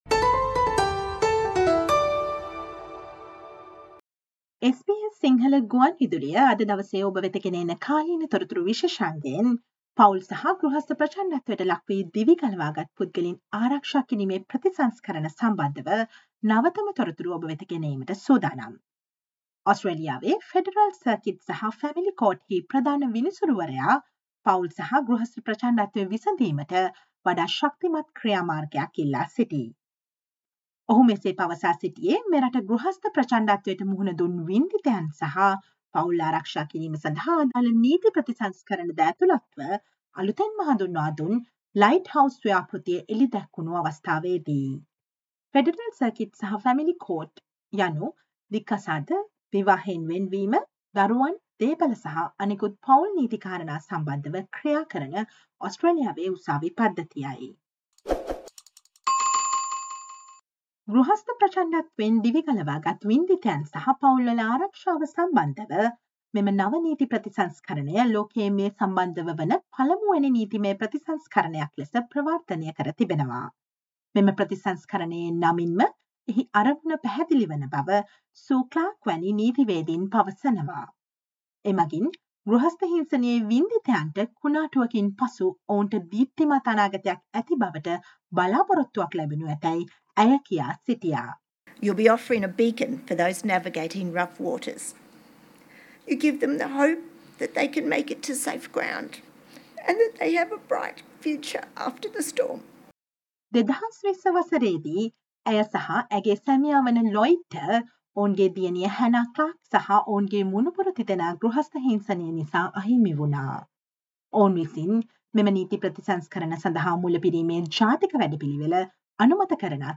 Today - 06 December, SBS Sinhala Radio current Affair Feature on the importance of ighthouse Initiative launched including reforms to the legal system to add protections for victim survivors and families.